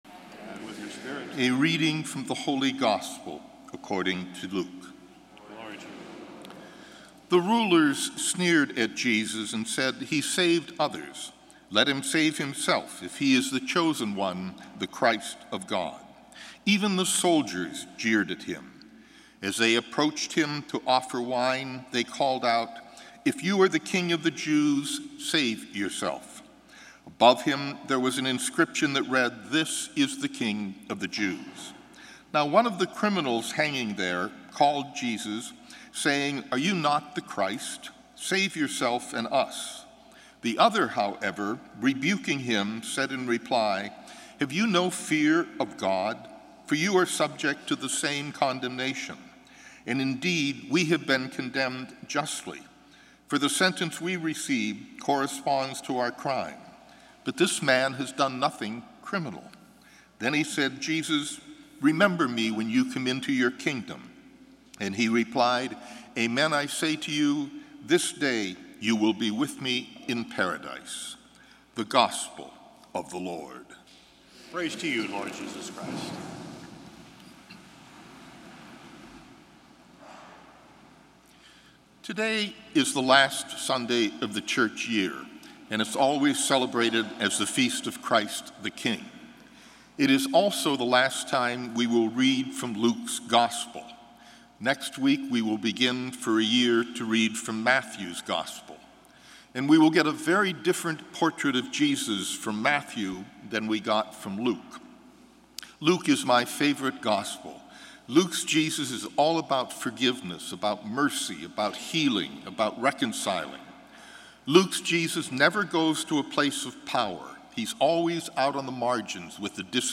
Gospel & Homily November 20, 2016